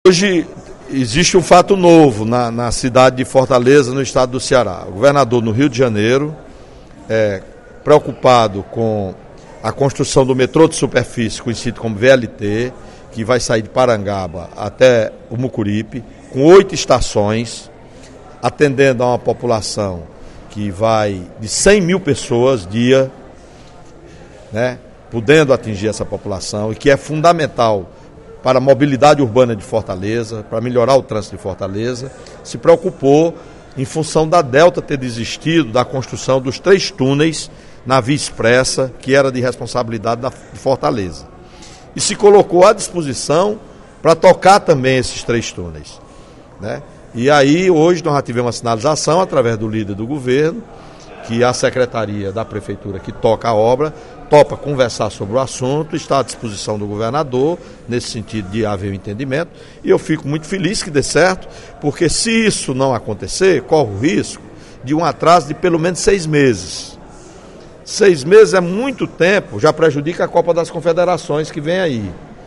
É preciso mais para socorrer os municípios do Ceará que sofrem com a seca. O apelo foi feito pelo deputado Welington Landim (PSB) na sessão plenária desta quinta-feira (31/05).